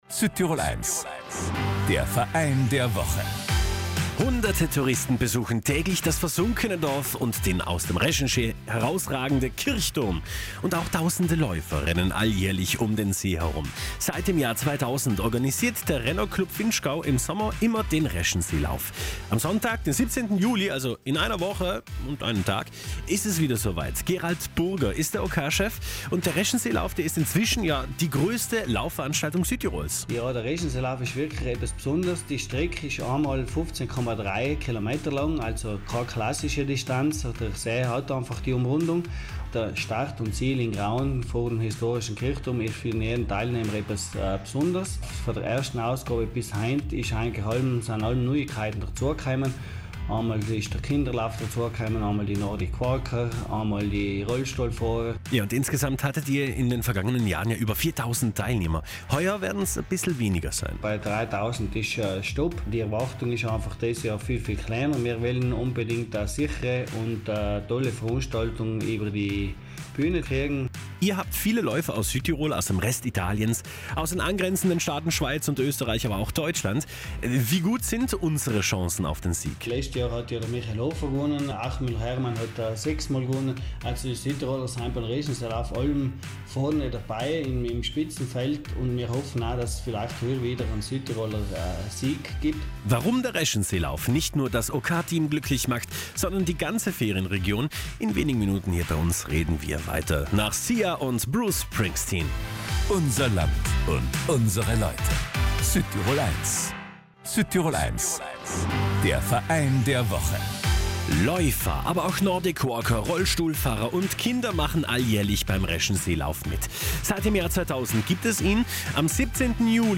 Die Kulisse traumhaft, aber wie „Zach“ ist dieser Lauf wirklich? Und wie läuft der Event heuer ab am 17. Juli? Das ganze Gespräch mit unserem Verein der Woche, dem OK-Team des Reschenseelaufs, gibt es hier zum Nachhören.